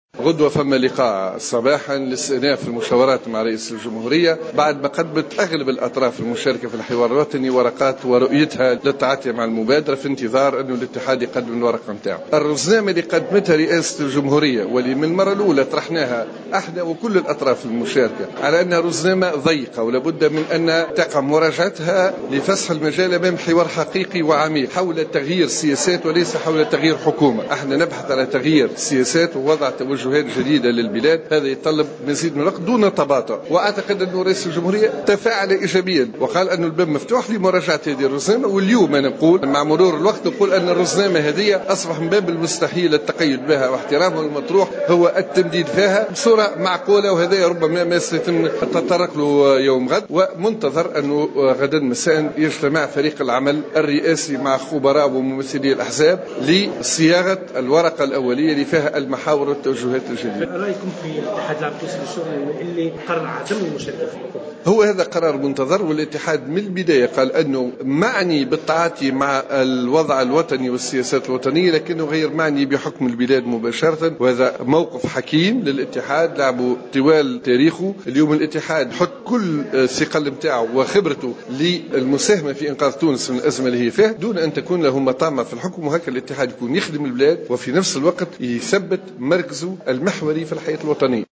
وأضاف الشابي في تصريح لمراسل "الجوهرة أف أم" أن الرزنامة التي قدمتها رئاسة الجمهورية ضيقة ولابد من مراجعتها لفسح المجال أمام حوار حقيقي وعميق حول تغيير سياسات وليس حكومة، وفق تعبيره.